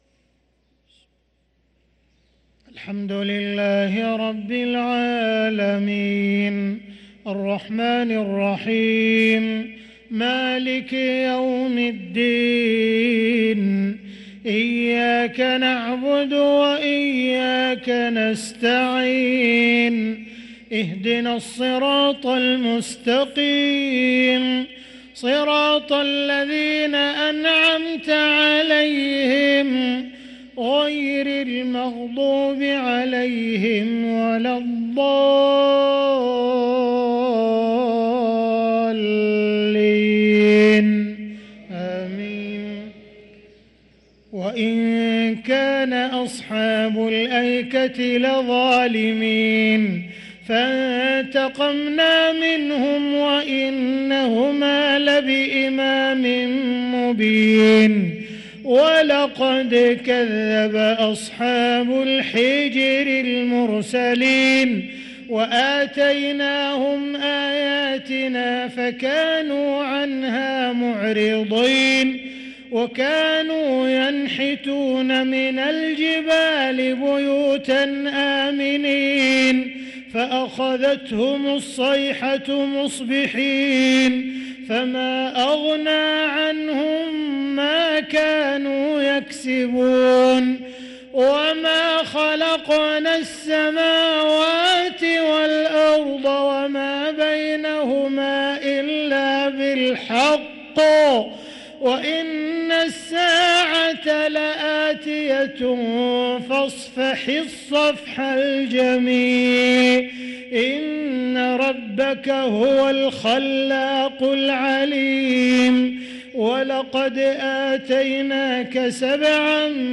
صلاة العشاء للقارئ عبدالرحمن السديس 7 جمادي الآخر 1445 هـ
تِلَاوَات الْحَرَمَيْن .